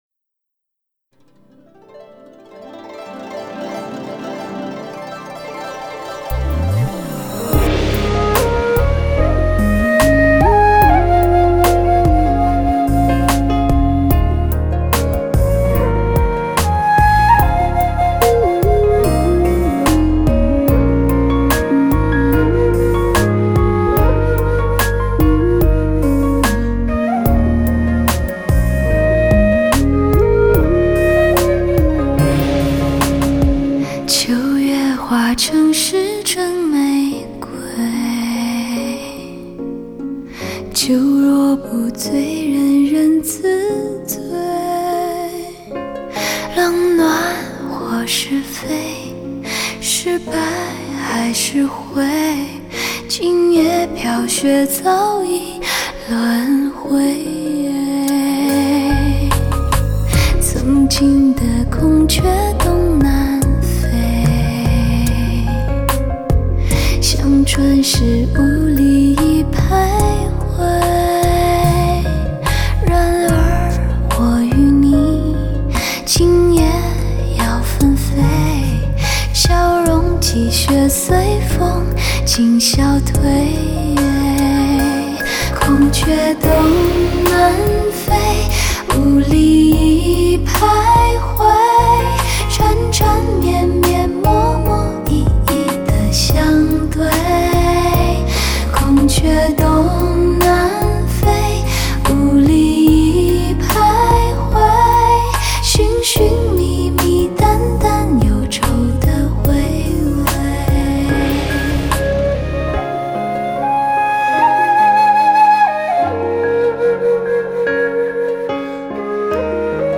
那种柔情似水的音色，那忧郁而又略带清纯的歌声，温柔得让人眩晕，让人窒息。